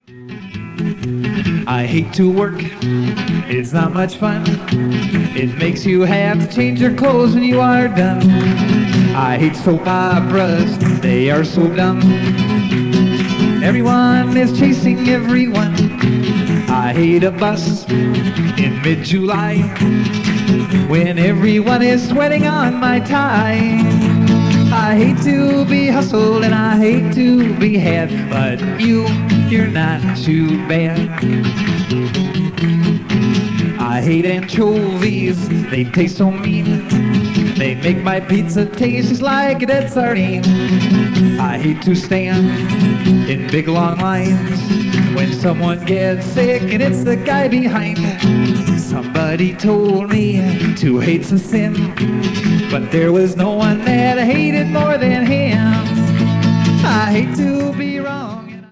on an old Teac 4-track, reel-to-reel recorder.